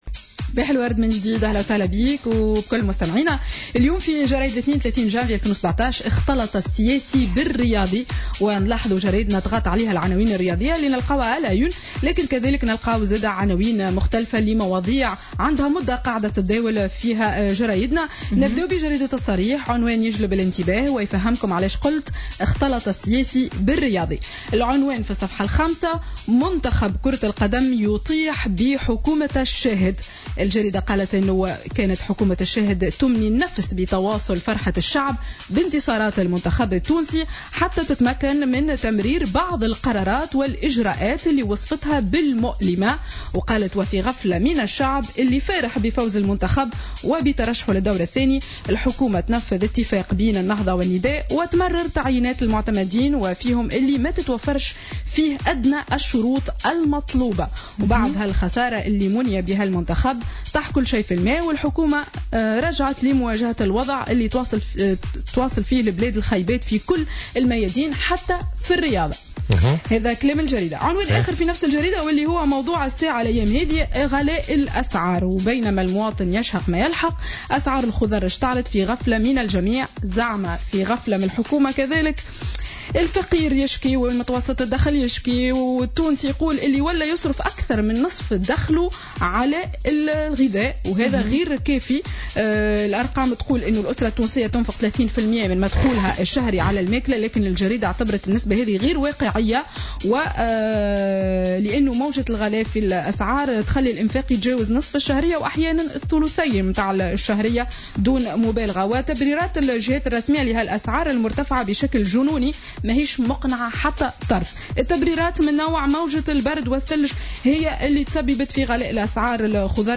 Revue de presse du lundi 30 janvier 2017